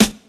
• '00s Nice Rap Snare Drum Sound G# Key 06.wav
Royality free snare one shot tuned to the G# note. Loudest frequency: 2316Hz
00s-nice-rap-snare-drum-sound-g-sharp-key-06-Hm5.wav